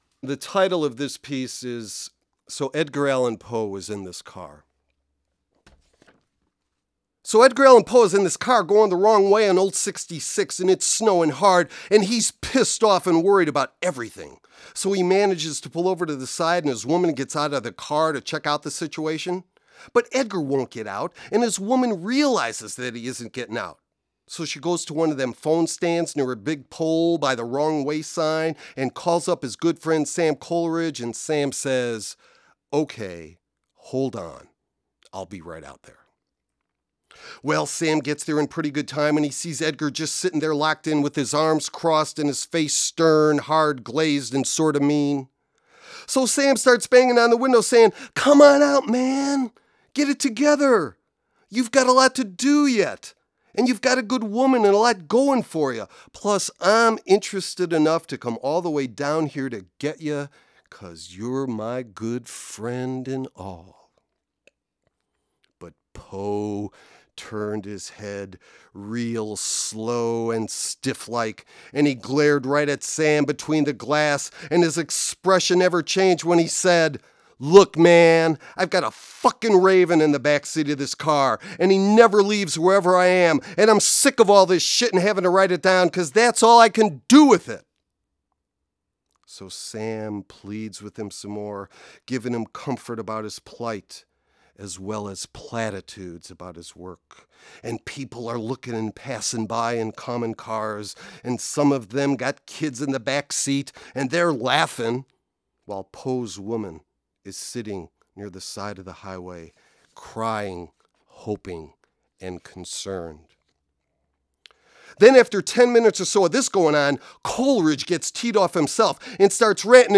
This is a collection of spoken word poetry and critical essays. The anthology also includes pictures, illustrations and a CD with audio performances of the poems.